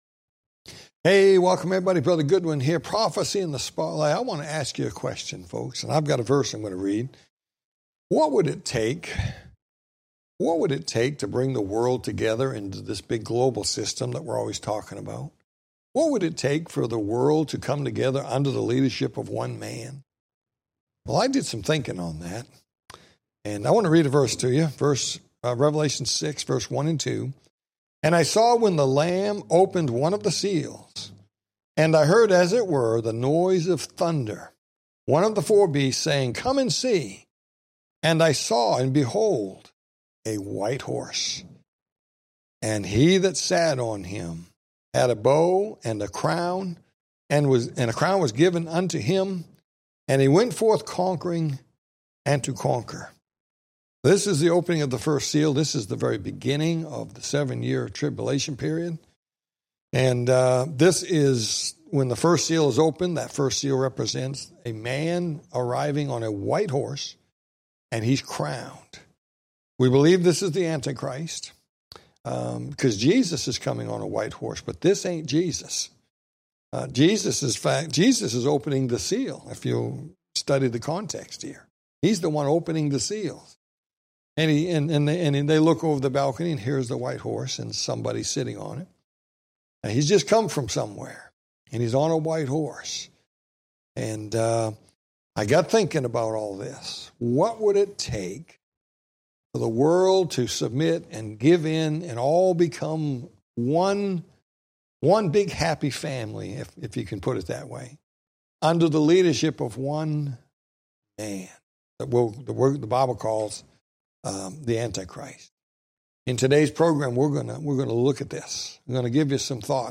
Talk Show Episode, Audio Podcast, Prophecy In The Spotlight and Road To Globalism, BRICS Nations Rising on , show guests , about Road To Globalism,BRICS Nations Rising, categorized as History,News,Politics & Government,Religion,Society and Culture,Theory & Conspiracy